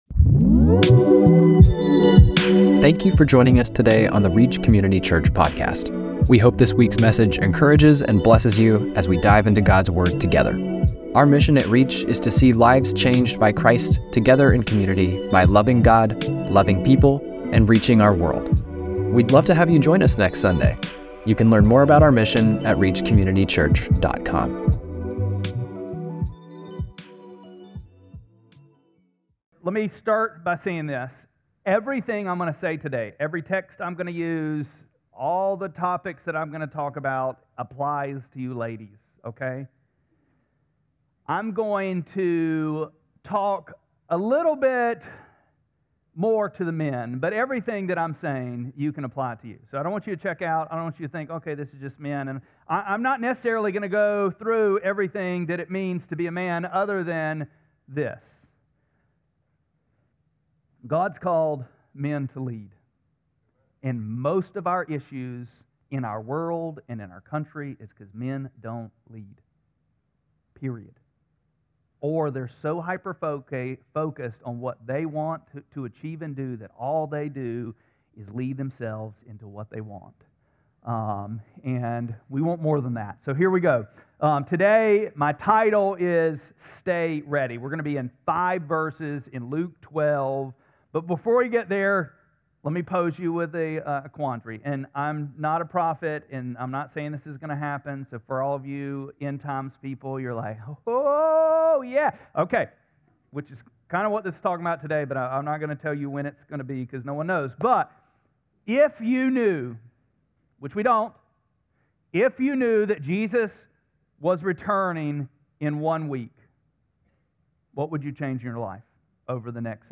4-19-26-Sermon.mp3